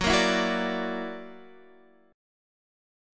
F#+7 chord